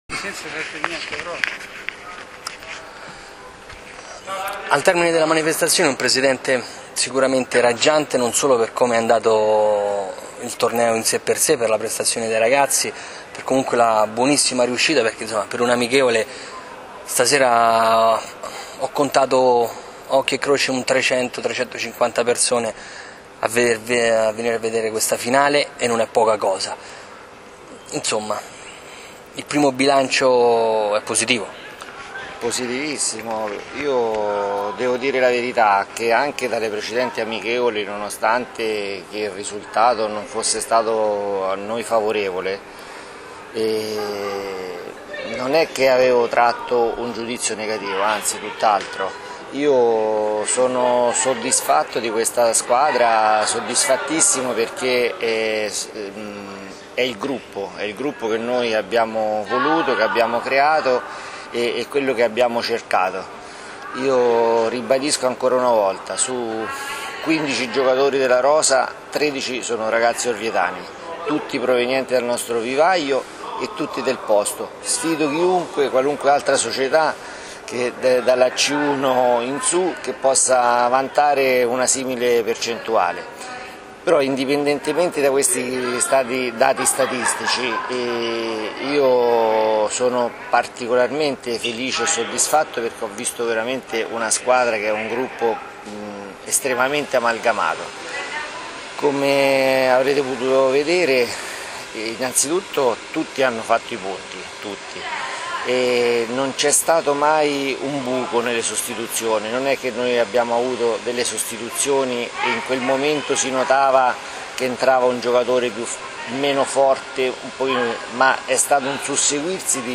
Le interviste del dopo gara